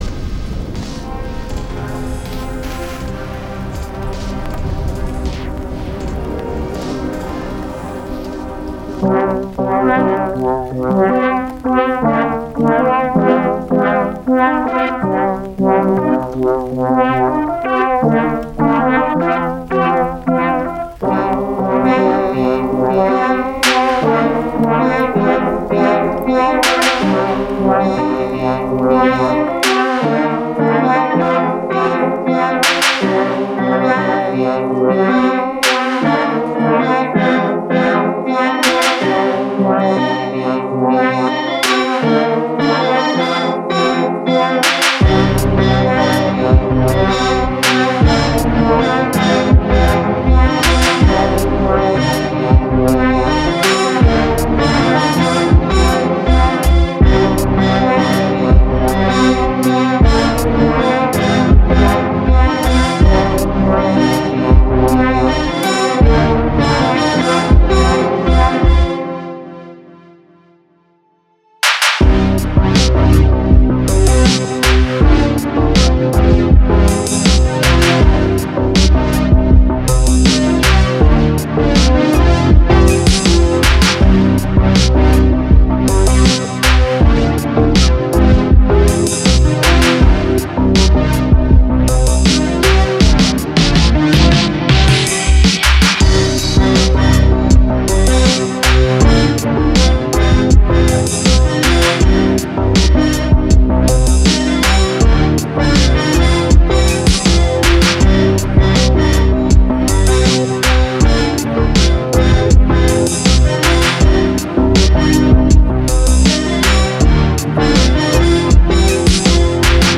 Genre Synthwave, Cyberpunk, Lo-Fi